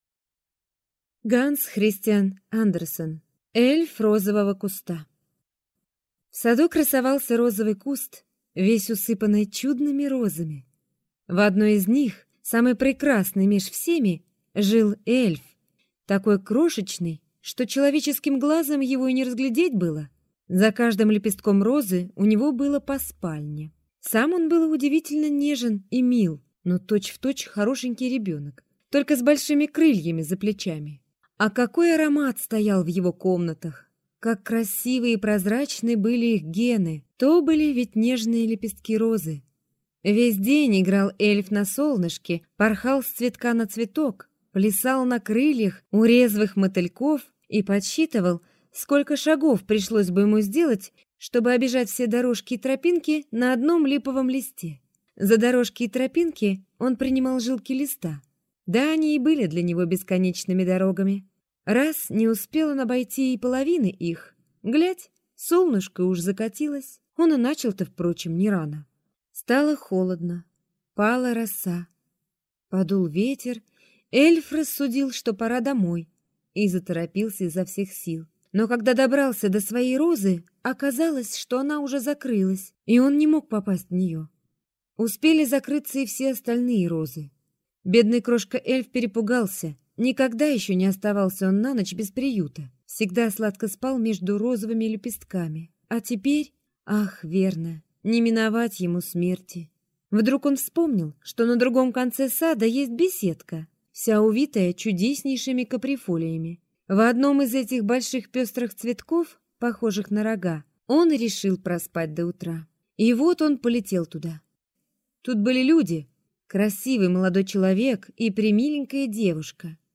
Аудиокнига Эльф розового куста | Библиотека аудиокниг